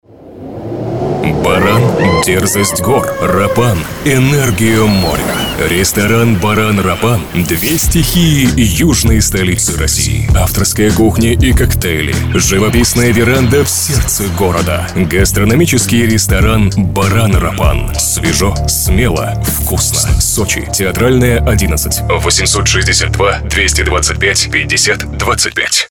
аудио ролик для ресторана.